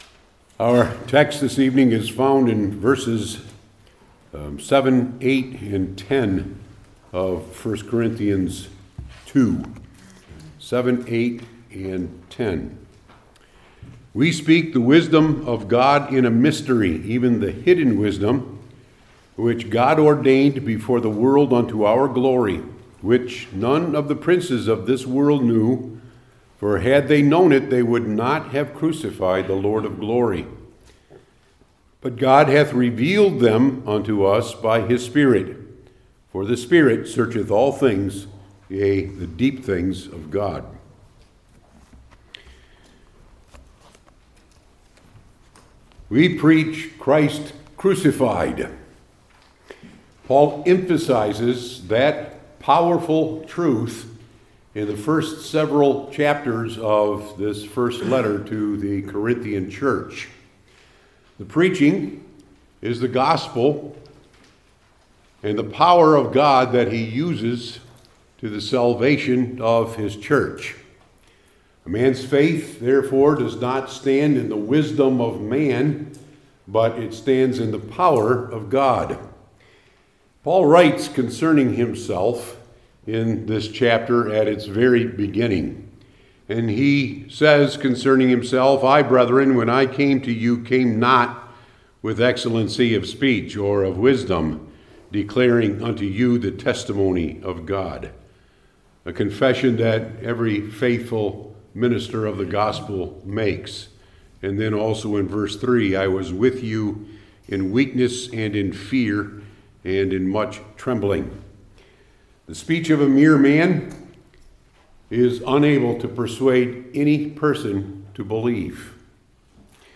New Testament Individual Sermons I. What It Is II.